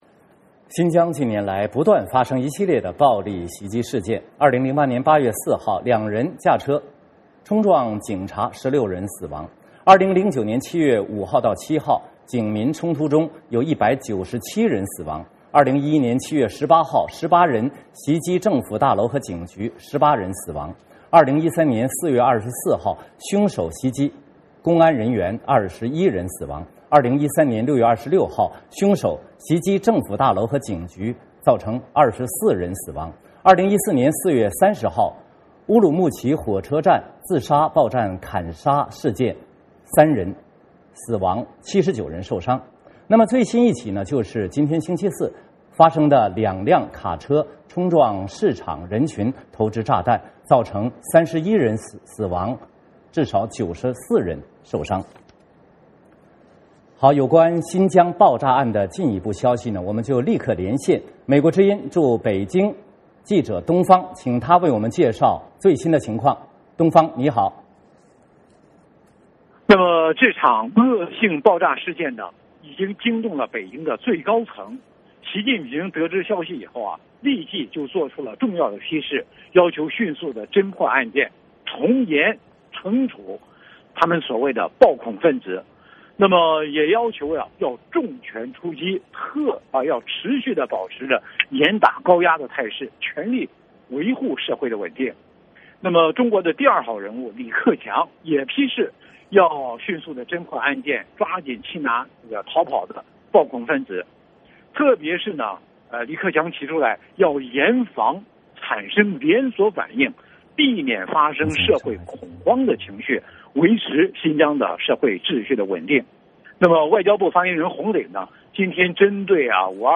VOA连线：新疆爆炸案最新发展